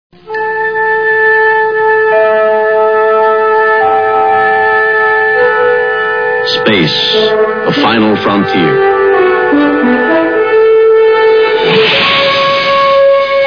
Beginning of show opening.